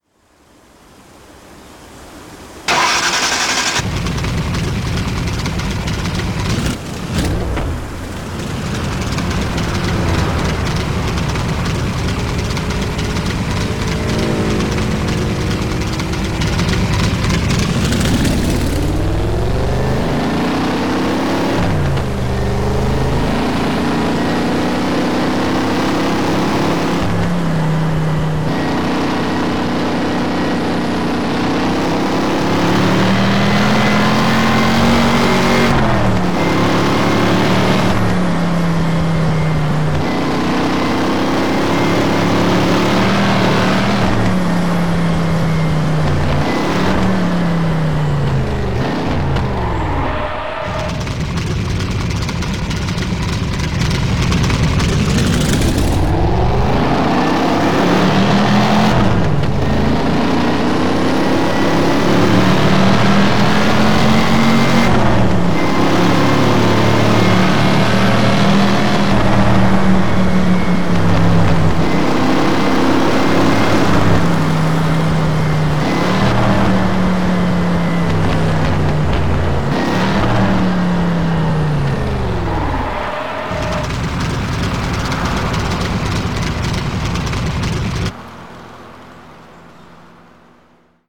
TDU 1 - Sound mods